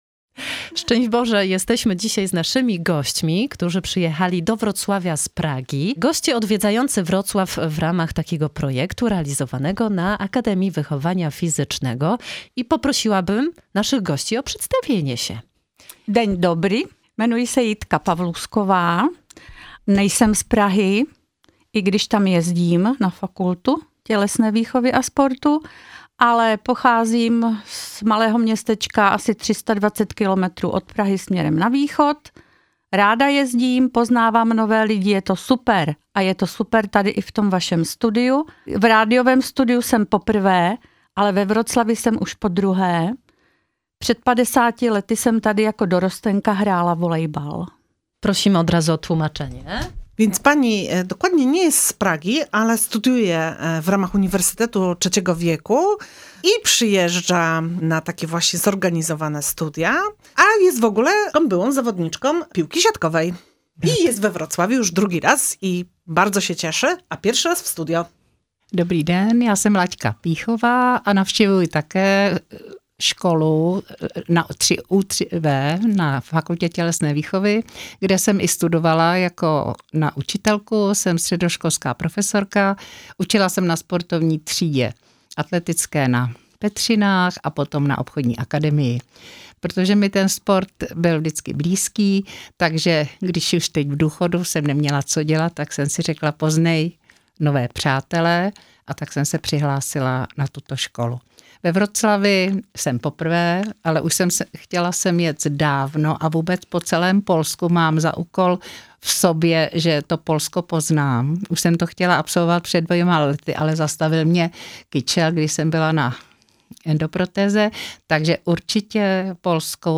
Panie poznały miasto i Ostrów Tumski, opowiadając o zwyczajach, kolędach Bożego Narodzenia oraz świątecznej kuchni prosto z Moraw!